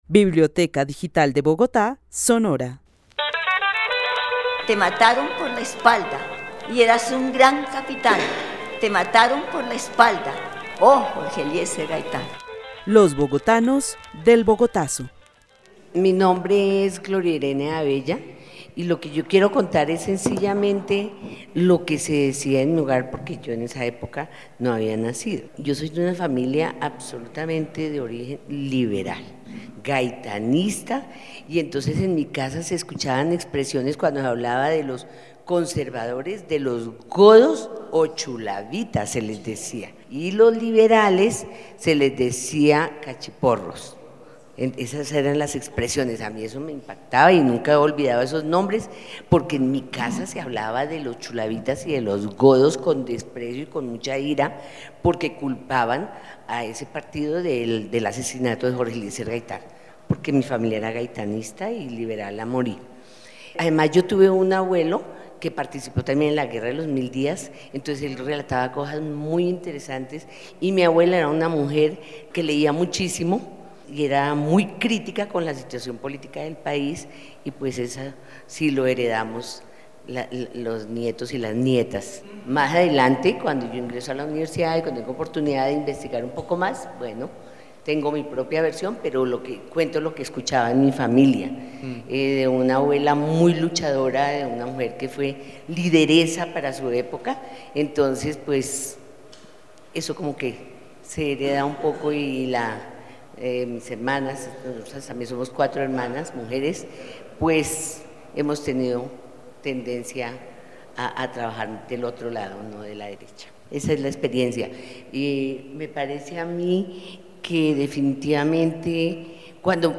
Narración oral donde relata lo que escuchó sobre lo acontecido el 9 de abril de 1948. Afirma que ella es de una familia liberal y gaitanista, quienes se referían con desprecio a los conservadores; además de la participación de sus abuelos en la Guerra de los Mil Días y su desplazamiento forzado a Bogotá.